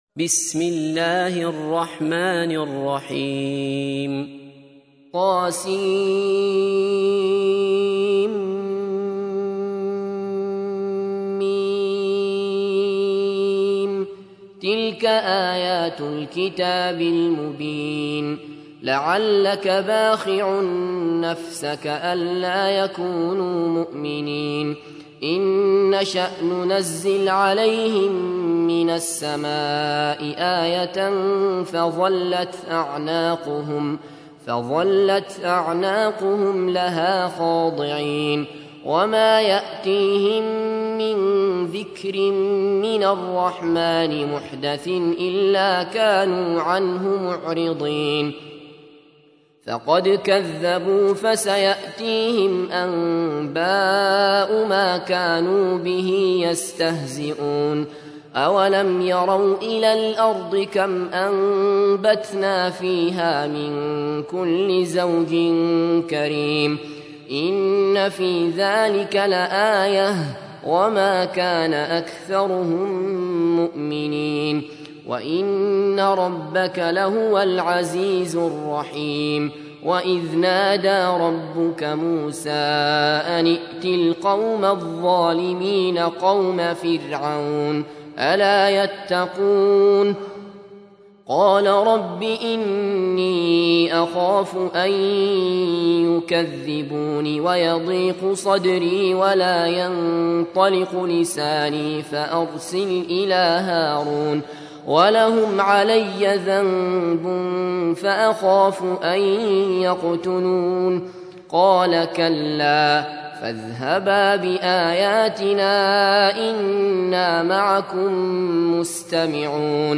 تحميل : 26. سورة الشعراء / القارئ عبد الله بصفر / القرآن الكريم / موقع يا حسين